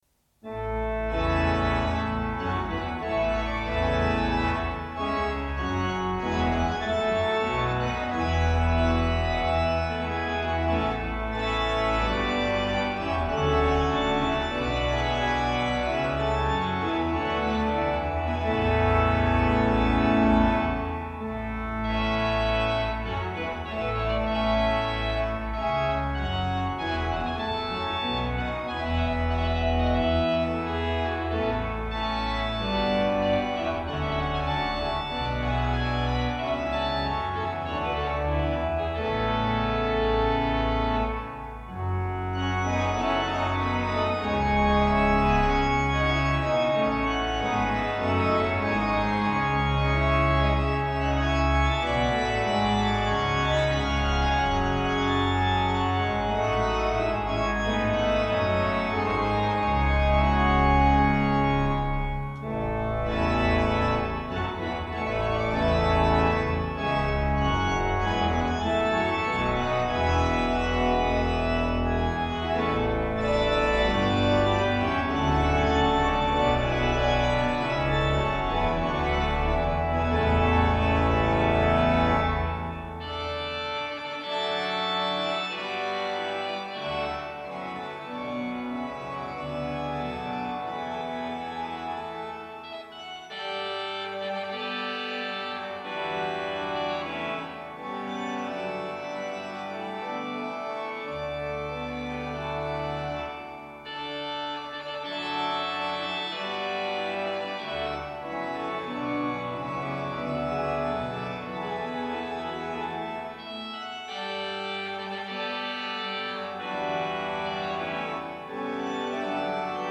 PD 477 plus Hauptwerk
Primarily, his interest is in the Harrison and Harrison pipe organ sound, for which Phoenix Organs is well known, as well as Casavant Freres and Aeolian Skinner.
The audio system includes a Lexicon reverb and delay system to electronically create a larger sound field.
Both the Hauptwerk and Phoenix systems can be played separately, or together, for a huge and impressive sounding organ of over 120 stops.